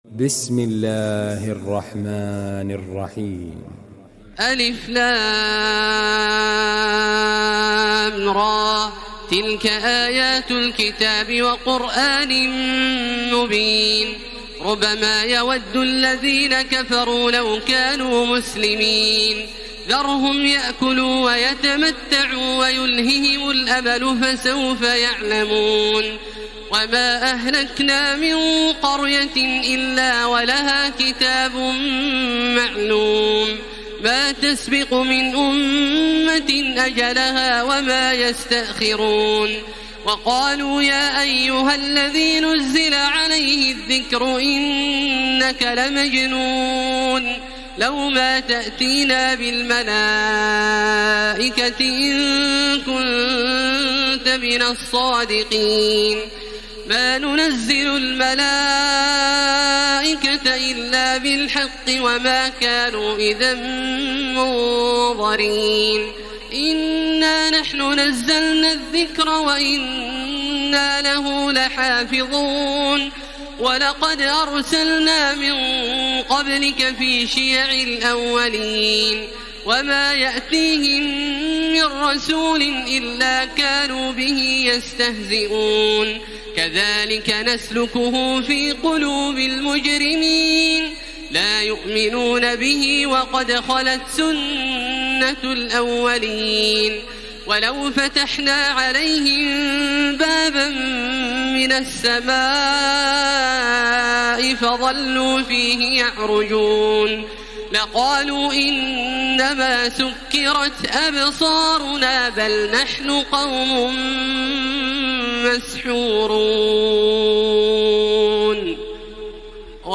تراويح الليلة الثالثة عشر رمضان 1433هـ من سورتي الحجر كاملة و النحل (1-52) Taraweeh 13 st night Ramadan 1433H from Surah Al-Hijr and An-Nahl > تراويح الحرم المكي عام 1433 🕋 > التراويح - تلاوات الحرمين